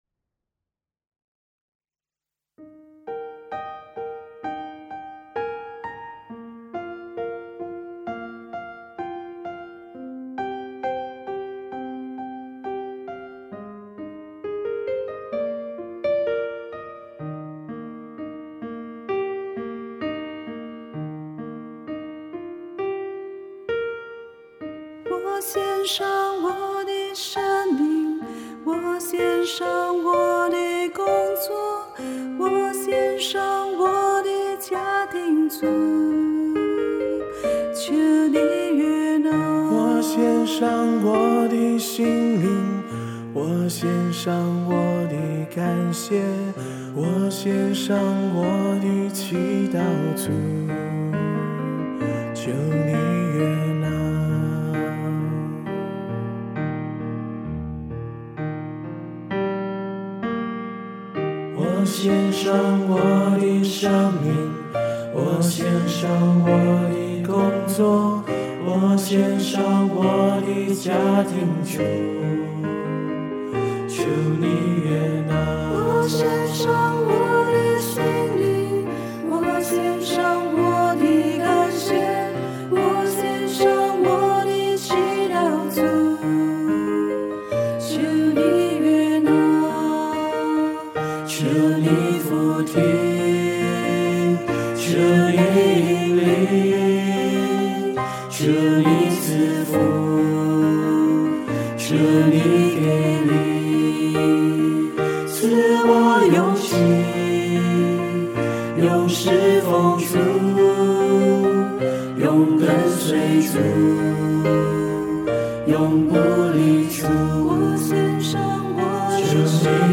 【原创圣歌】